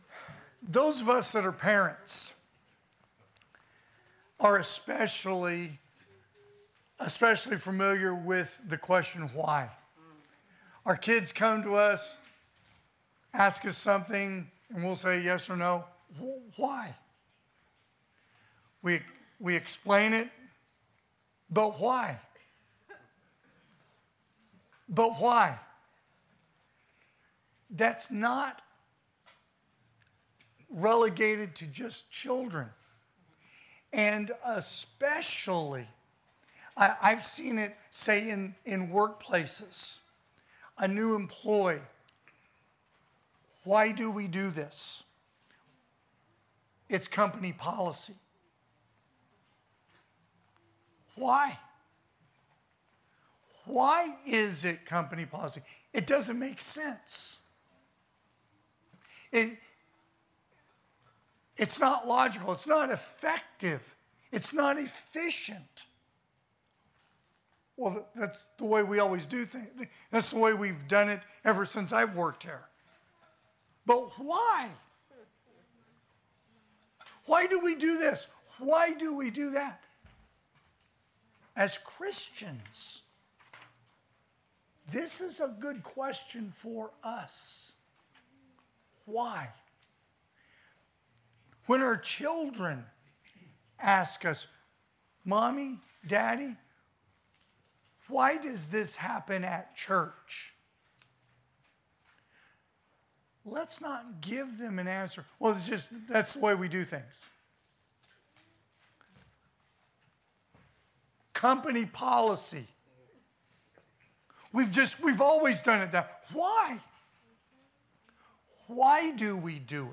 January 5,2025 (AM Worship) “Why?
Sermons